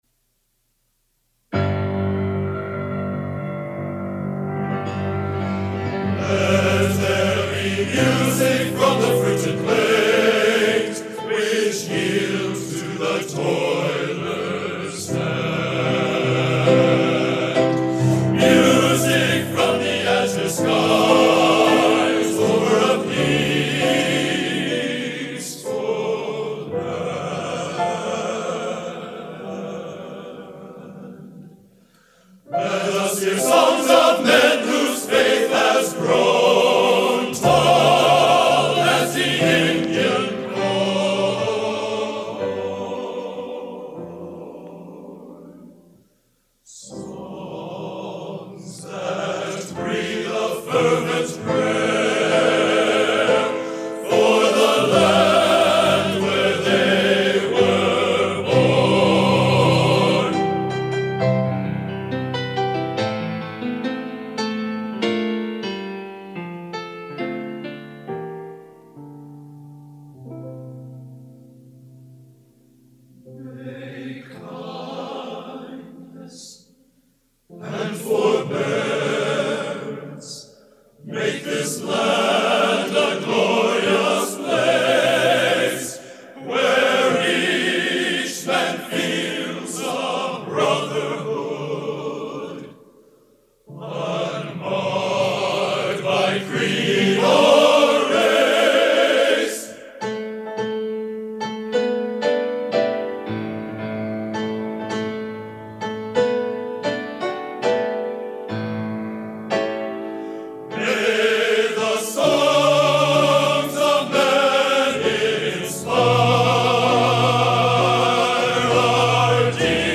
Genre: Collegiate | Type: Studio Recording